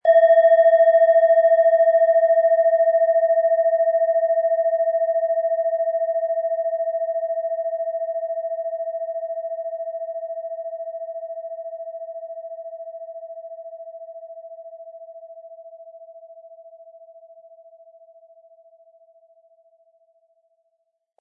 Hopi Herzton
Es ist eine von Hand getriebene Klangschale, aus einer traditionellen Manufaktur.
MaterialBronze